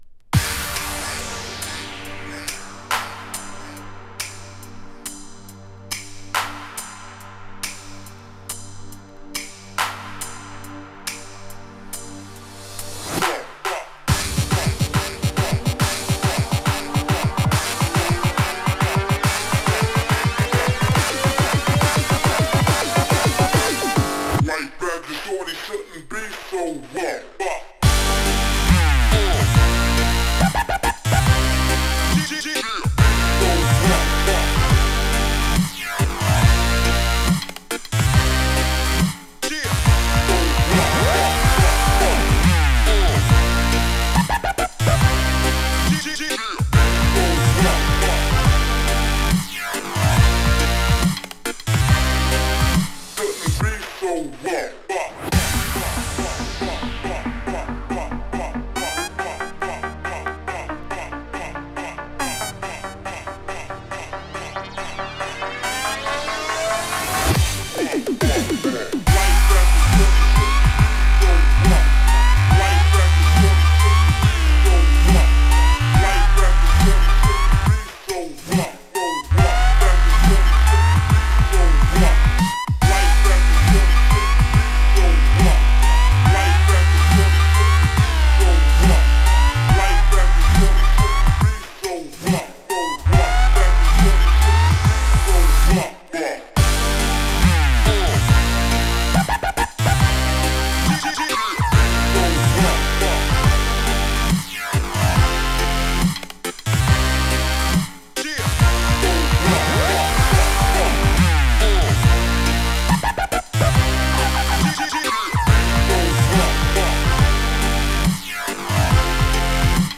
3. > BASS / DUB STEP / DRUM N' BASS